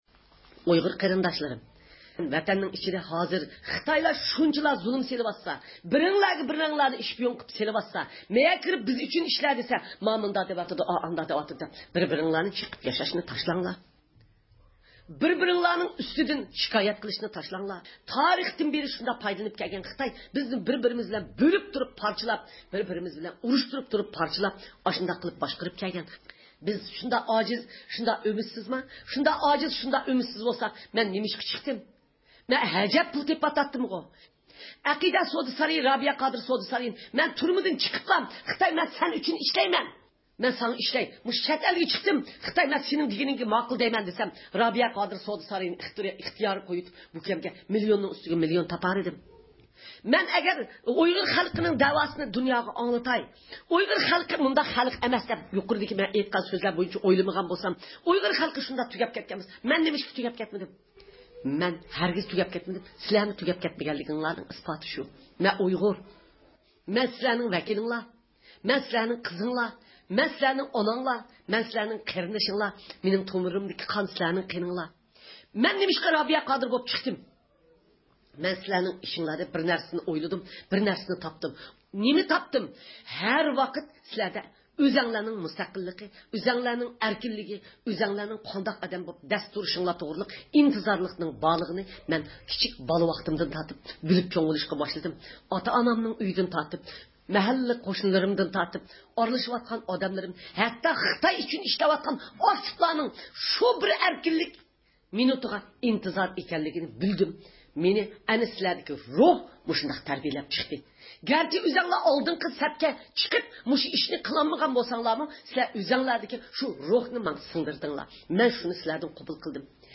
ئۇيغۇر مىللىي ھەركىتىنىڭ رەھبىرى، دۇنيا ئۇيغۇر قۇرۇلتىيىنىڭ رەئىسى رابىيە قادىر خانىم گېرمانىيىنىڭ ميۇنخېن شەھىرىدىكى زىيارىتى داۋامىدا شەرقى تۈركىستان ئىنفورماتسيۇن مەركىزى تەسىس قىلغان ئۇيغۇر تېلېۋىزىيىسىدە نوتۇق سۆزلىدى.